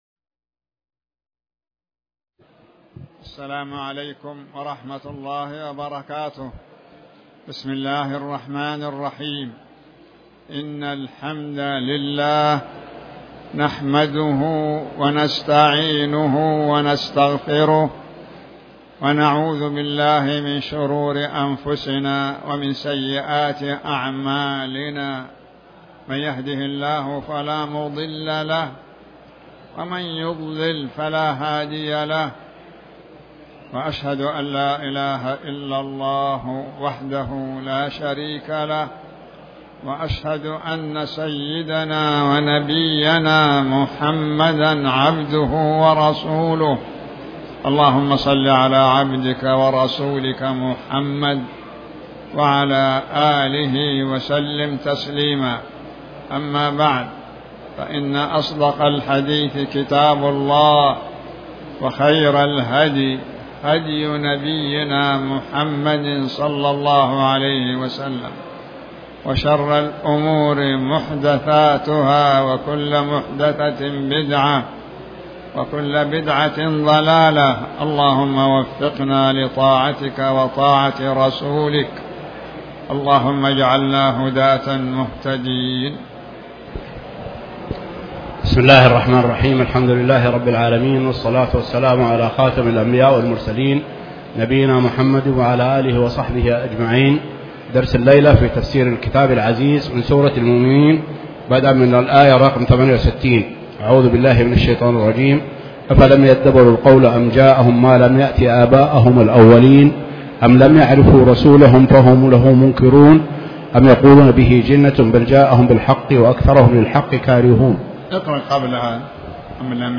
تاريخ النشر ١٦ محرم ١٤٤٠ هـ المكان: المسجد الحرام الشيخ